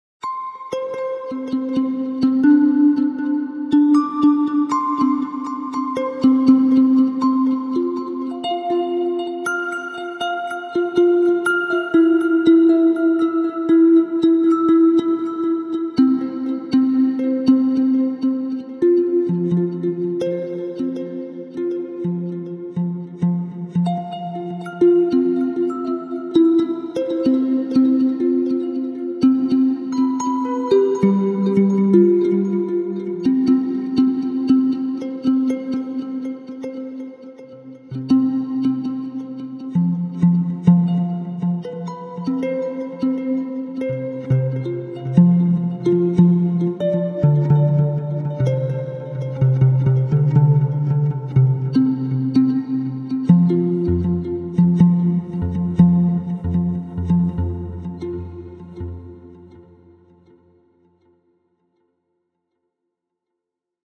Wenn Ihr mögt, noch ein kleiner Soundteppich dazu…